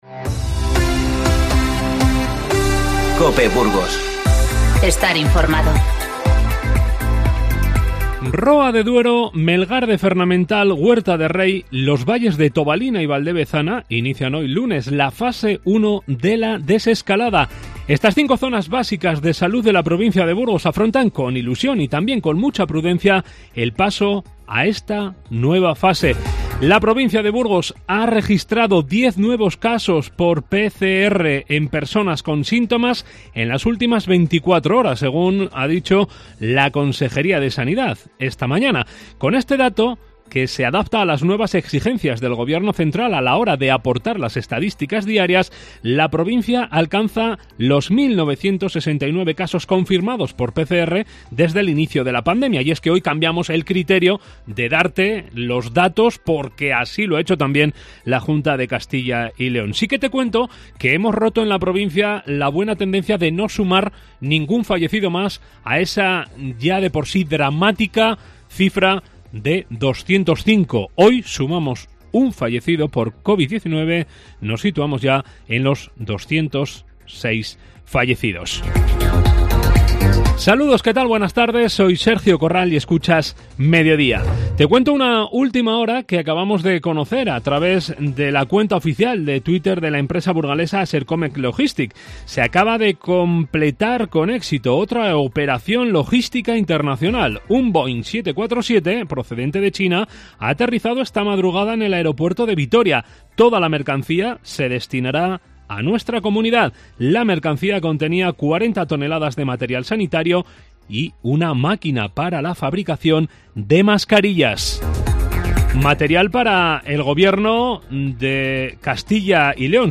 INFORMATIVO MEDIODÍA 18/5